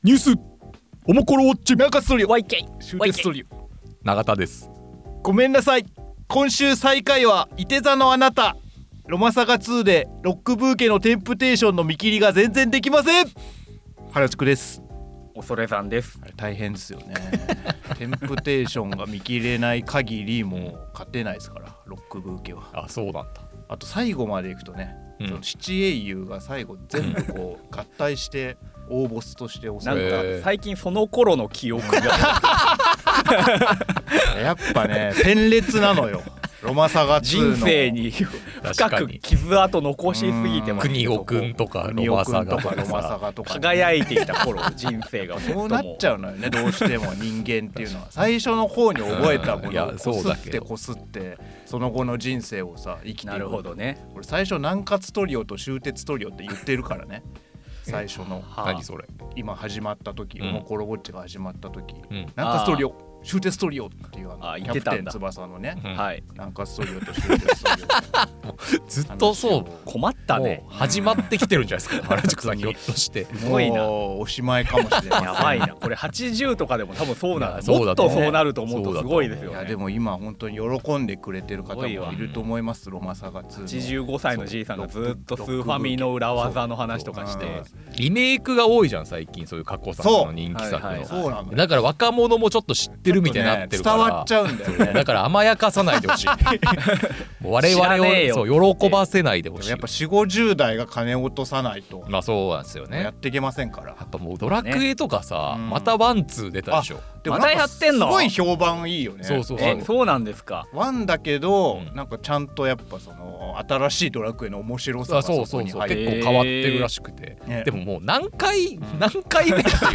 オモコロ編集部の3人が気になるニュースについて語ります。